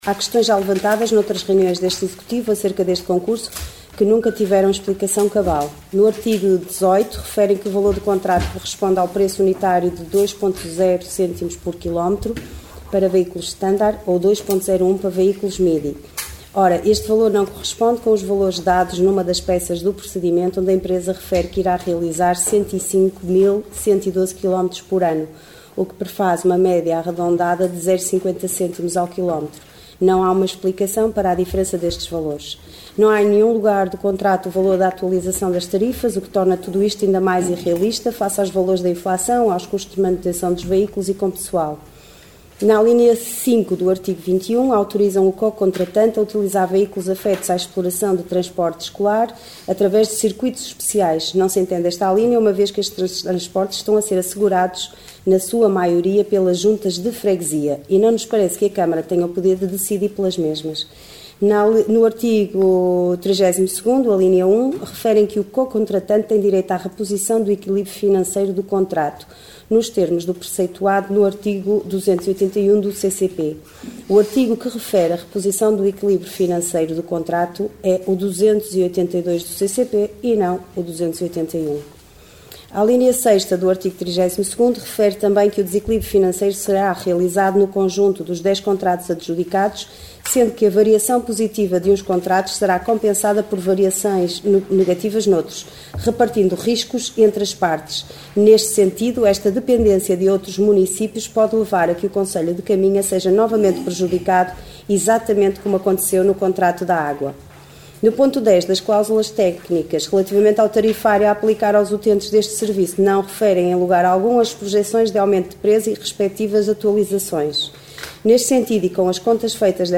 Numa declaração de voto apresentada ontem na reunião de câmara, a vereadora da Coligação OCP, Liliana Silva, explica detalhadamente o que levou a sua coligação a votar contra este concurso público.